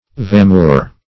Search Result for " vamure" : The Collaborative International Dictionary of English v.0.48: Vamure \Va"mure\, n. See Vauntmure .
vamure.mp3